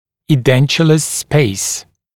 [iː’dentjuləs speɪs][и:’дэнтйулэс спэйс]пространство зубной дуги без зубов